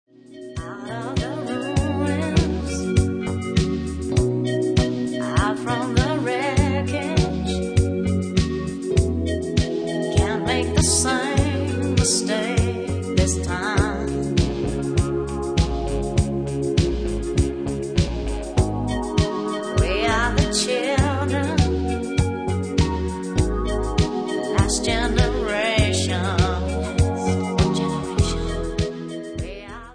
R&B KARAOKE MUSIC CDs
w/vocal